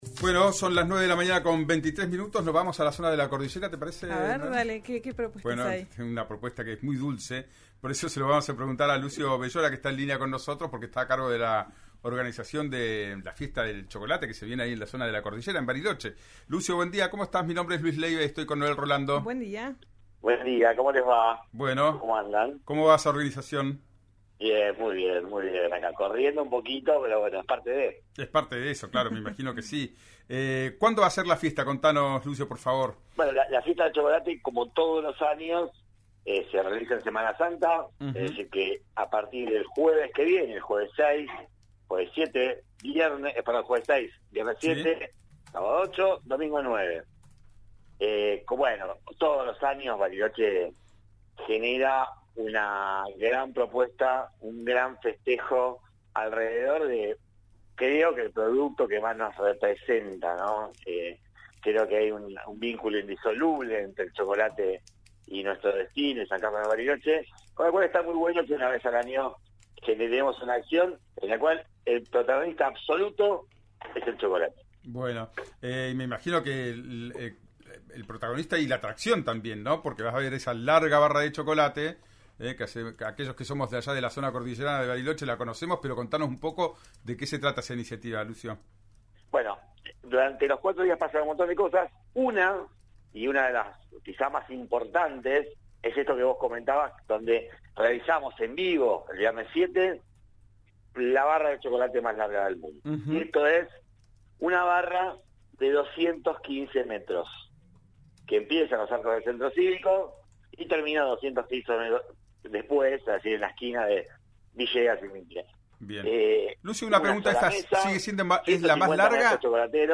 Sintonizá RÍO NEGRO RADIO.